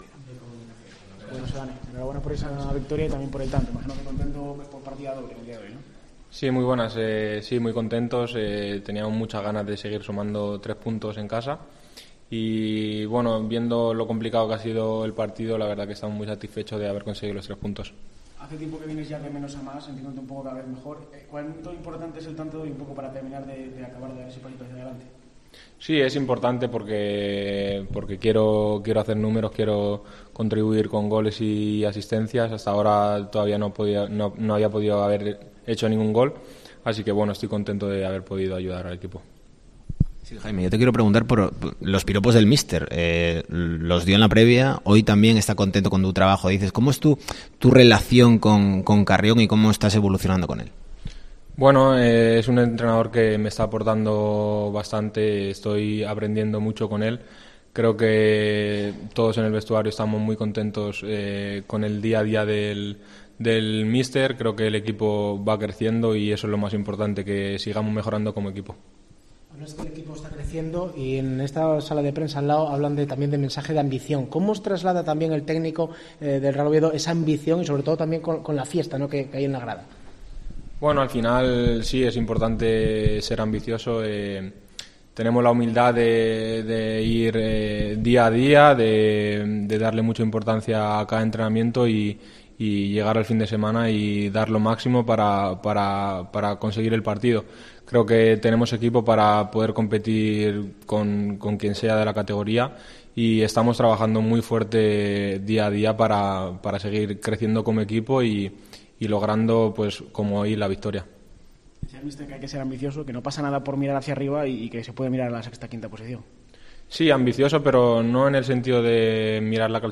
ZONA MIXTA